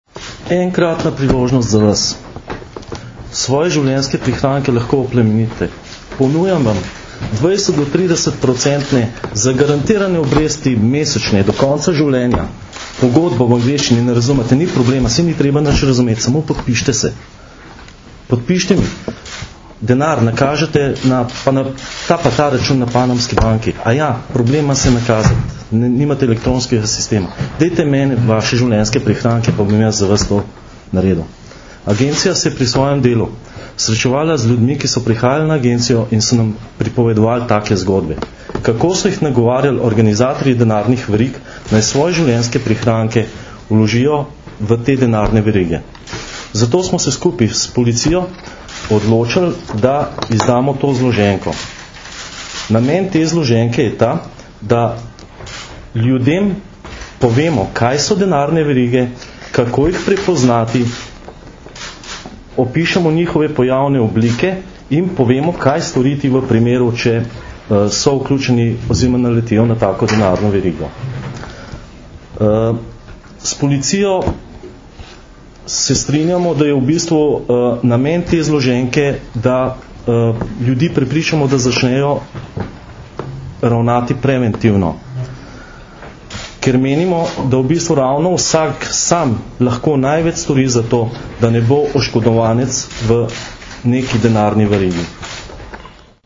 Policija in ATVP izdali zloženko o denarnih verigah - informacija z novinarske konference
Zvočni posnetek izjave mag.